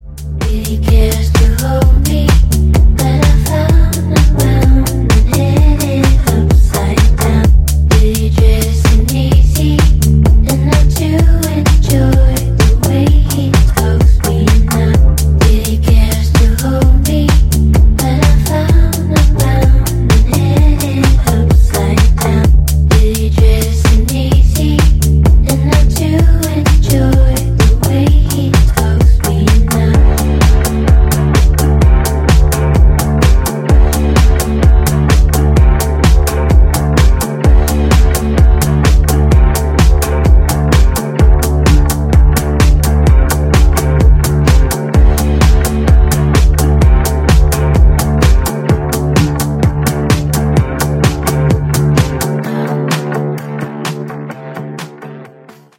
Скачать рингтон Электронный рингтон 2025
Категория: электронные , 2025 , Громкие рингтоны